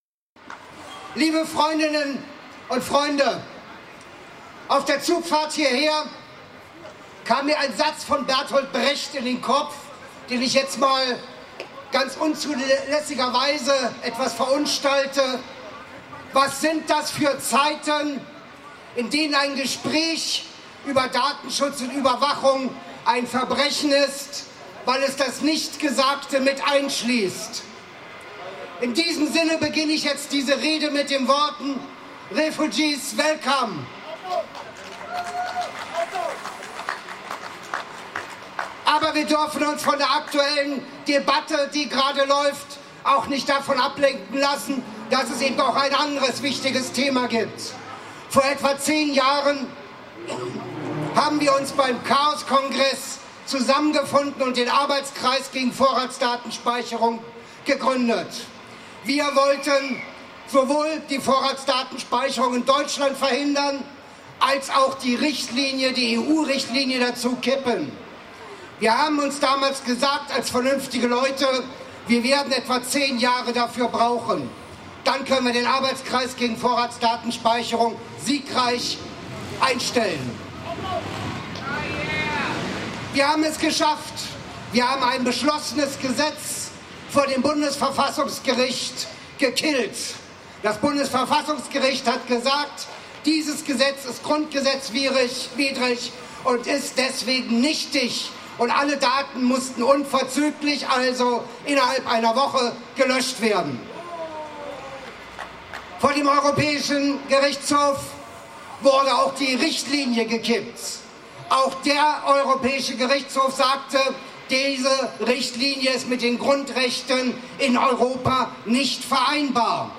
:Demonstration „Freiheit statt Angst“:
Dank bestem Sommerwetter sowie hochrangiger Besetzung fanden sich mehrere Hundert Individuen ein, um an der Demonstration „Freiheit statt Angst“[1] gegen Vorratsdatenspeicherung[2, 3], Überwachungsstaat, Internetzensur, Spionage durch Geheimdienste und für Netzneutralität, Bandbreitenausbau, sowie Förderung und Einsatz freier Software in öffentlichen Stellen zu demonstrieren. Hier sind die Ansprachen und musikalischen Beiträge der Kundgebung.
Ansprache